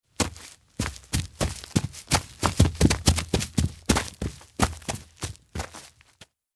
Movimientos de soldado escabulléndose del enemigo